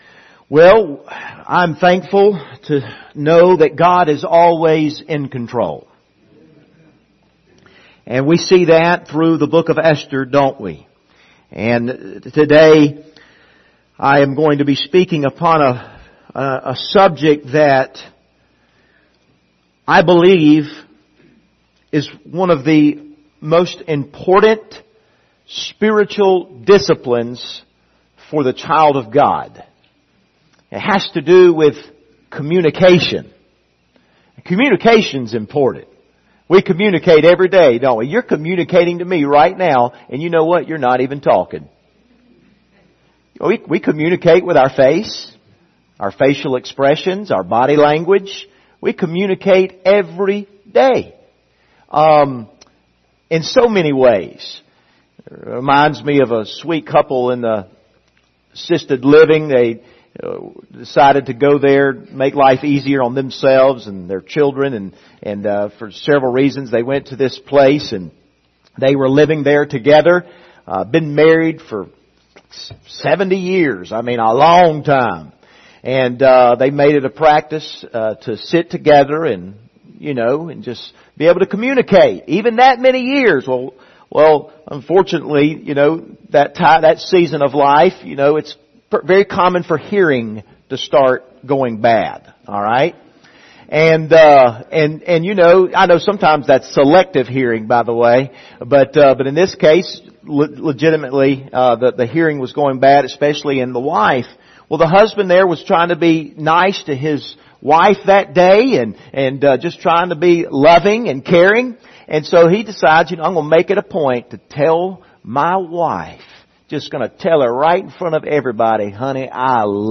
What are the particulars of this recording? Passage: Esther 4 Service Type: Sunday Morning